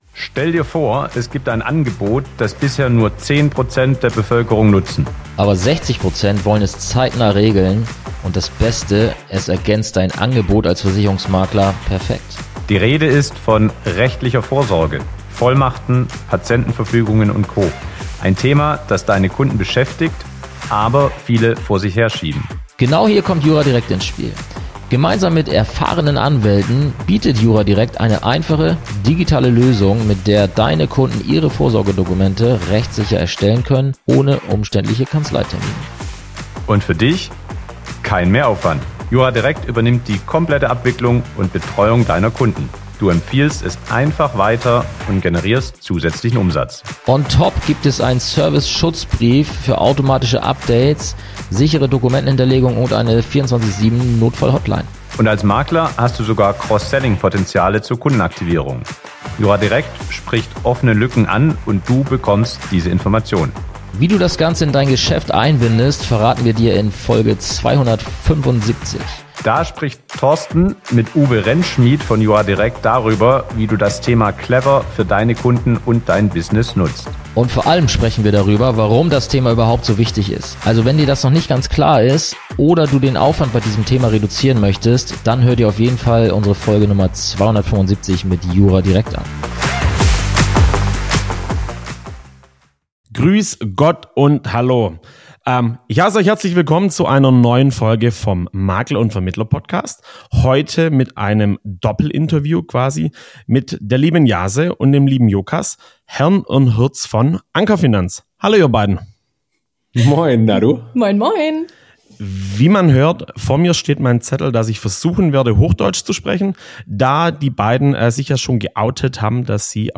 Die beiden kommen direkt aus dem hohen Norden Deutschlands, genauer gesagt aus Hamburg, und stehen im Mittelpunkt eines spannenden Doppelinterviews. Zu Beginn werden humorvoll ein paar schnelle Fragen beantwortet, bevor wir tief in die Welt des Maklerwesens eintauchen. Wir erfahren, warum Anker Finanz im vergangenen Jahr im Finale des Jungmakler Awards stand und wie Mentoren sie inspiriert haben, ihr Konzept grundlegend zu überdenken.